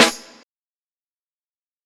GD snare.wav